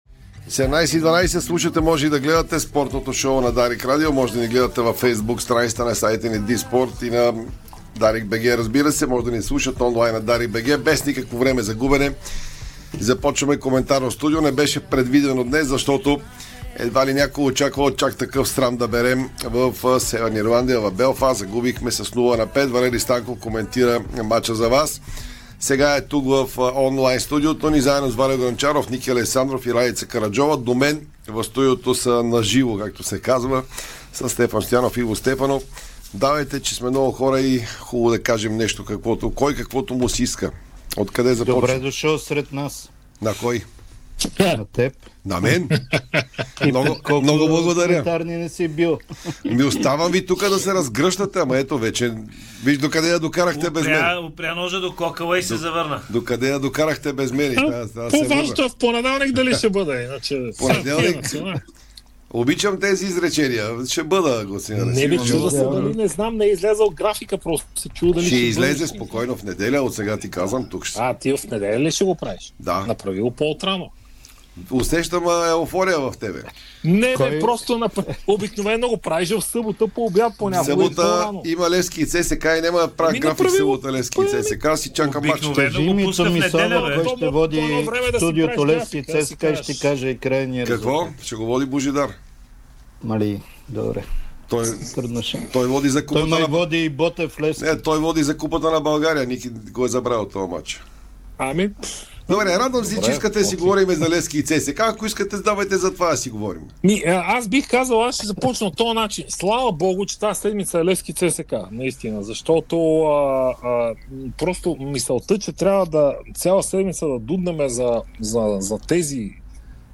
Коментарно студио след крушението на България в Северна Ирландия (ВИДЕО)